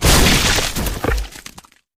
gore7.ogg